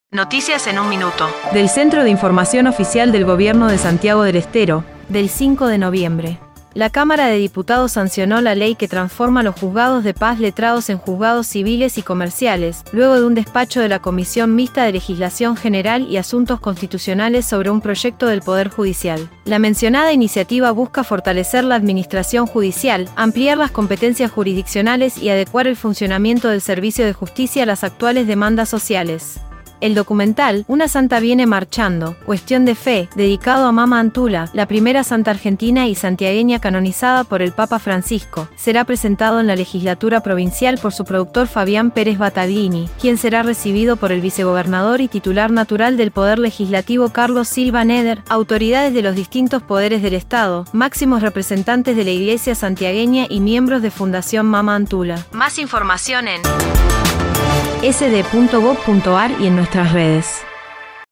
En 1 minuto el reporte de hoy